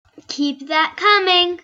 explo.mp3